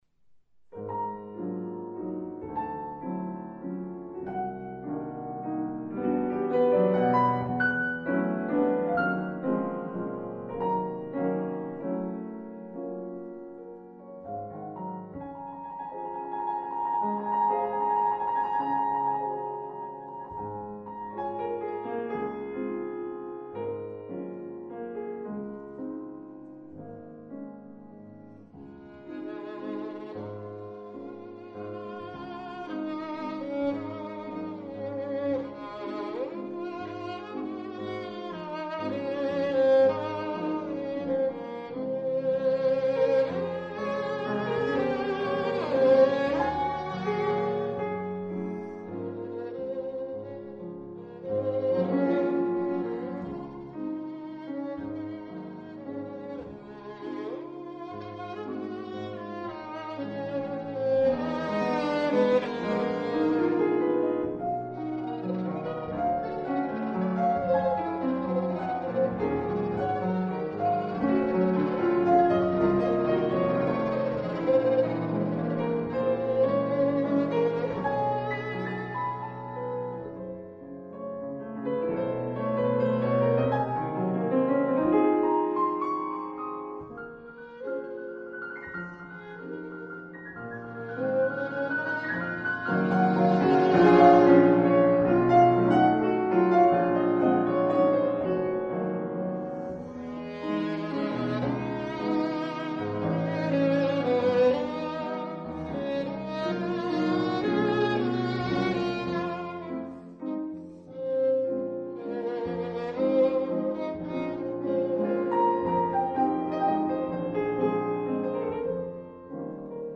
per violino e pianoforte